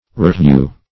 Roughhew \Rough"hew`\, v. t.